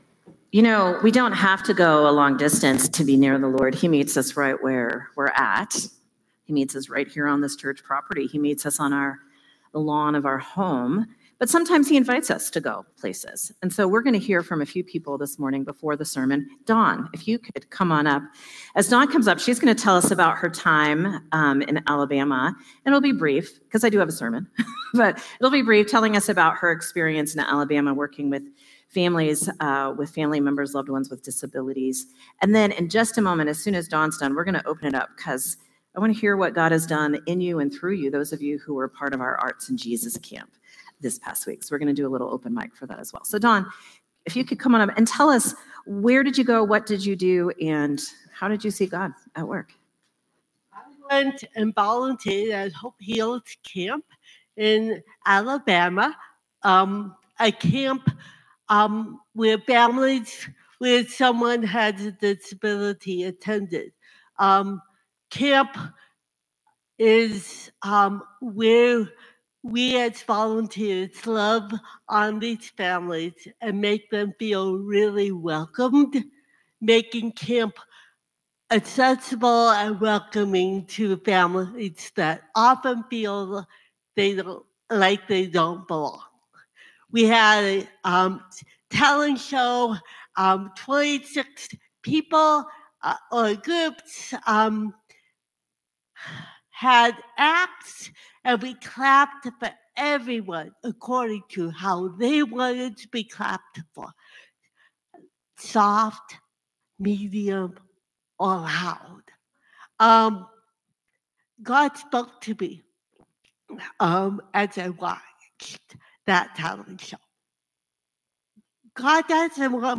Sermon from Celebration Community Church on July 27, 2025